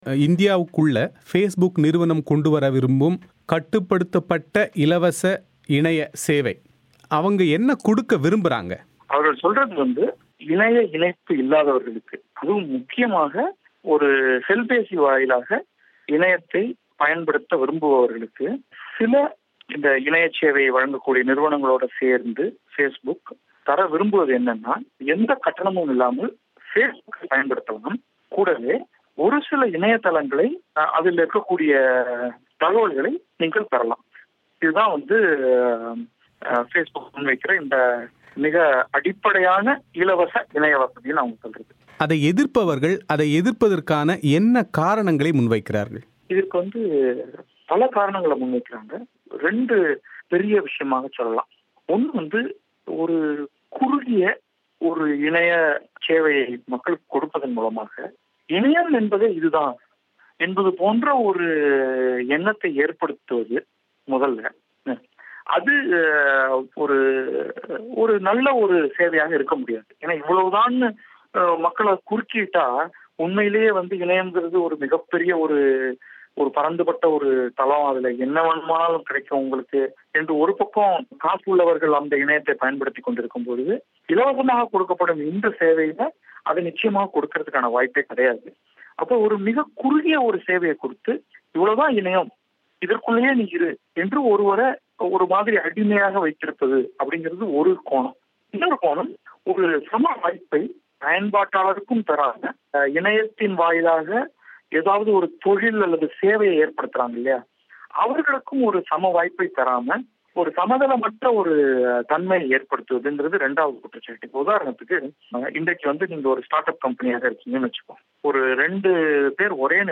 பிபிசி தமிழோசைக்கு அளித்த விரிவான செவ்வியை